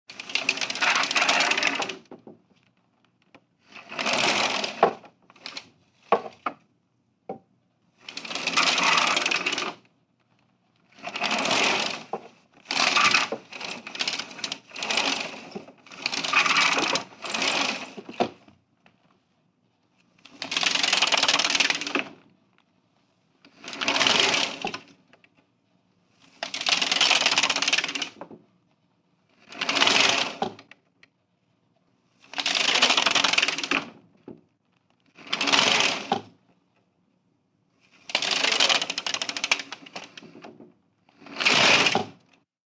DOORS/STRUCTURES WINDOWS: Venetian blinds, various ups & downs.